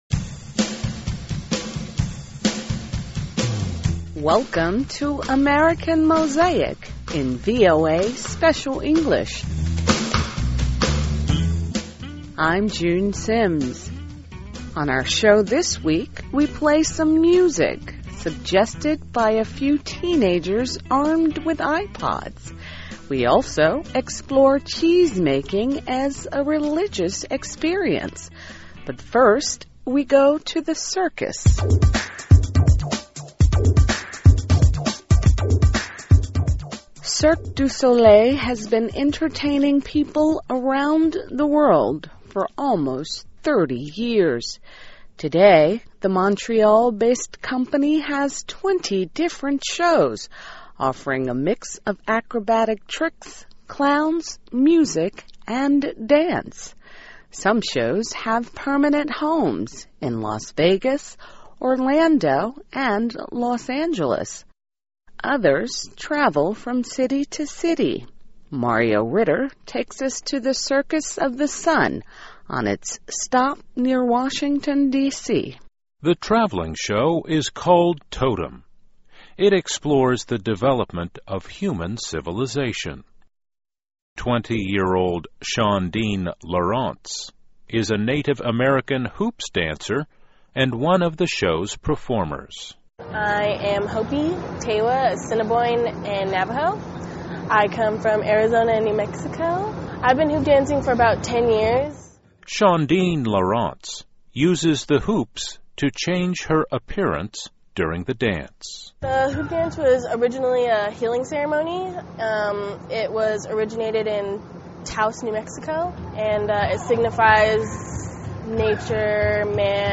2012 Welcome to AMERICAN MOSAIC in VOA Special English.